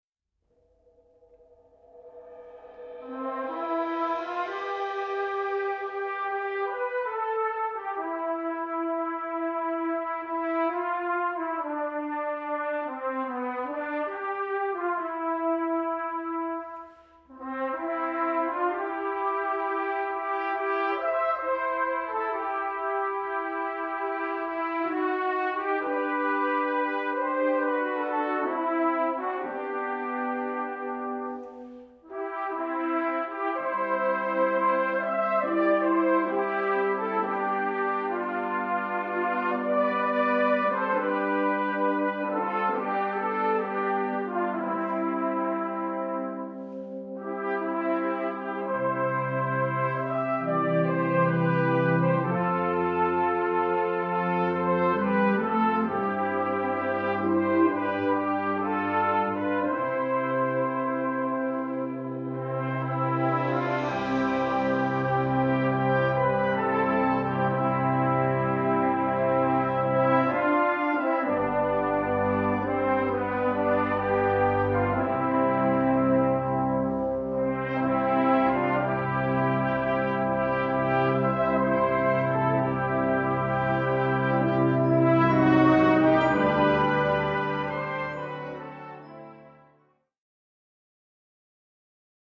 2:00 Minuten Besetzung: Blasorchester PDF